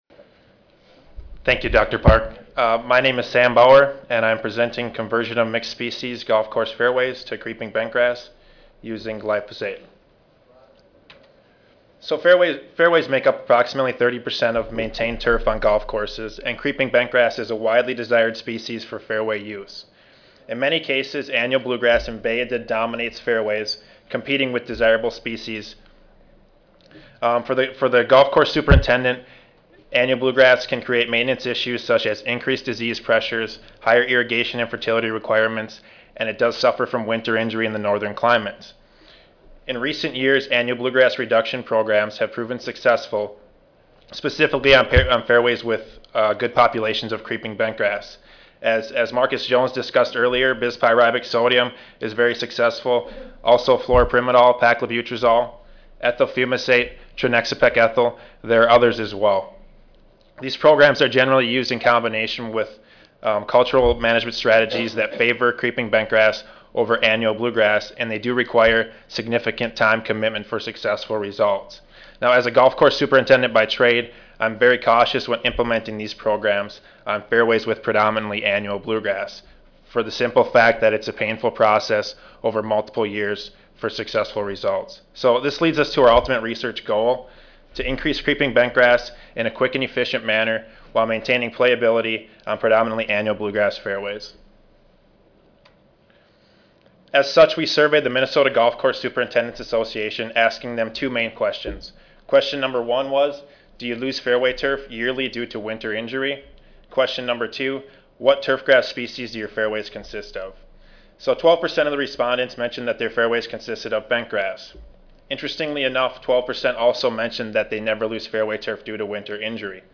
Recorded presentation